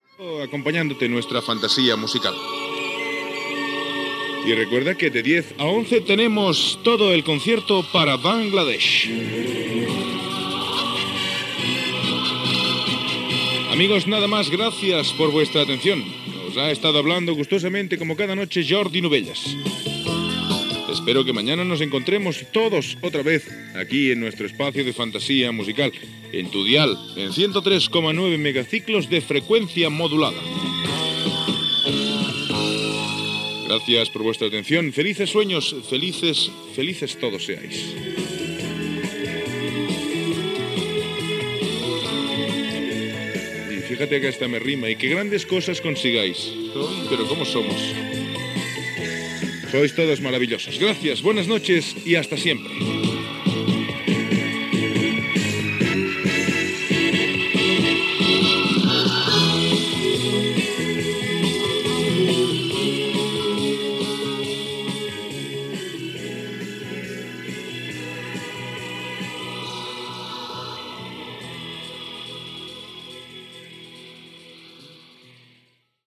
Fantasía musical Descripció Comiat del programa Gènere radiofònic Musical